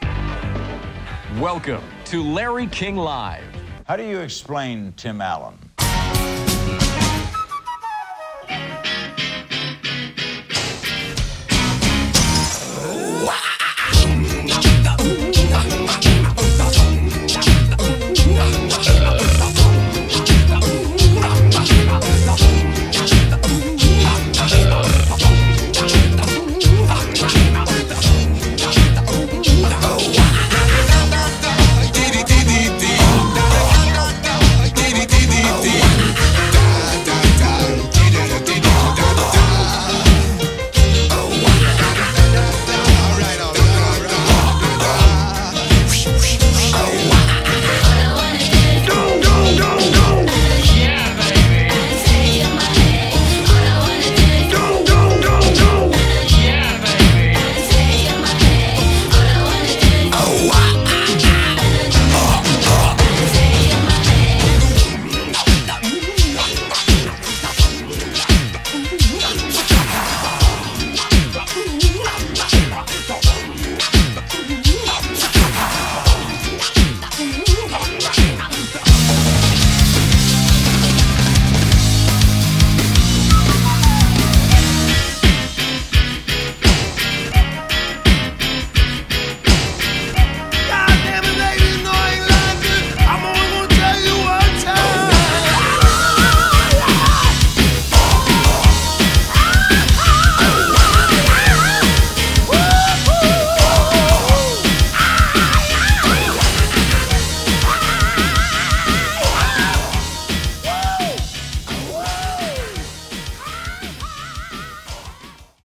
BPM85
Audio QualityMusic Cut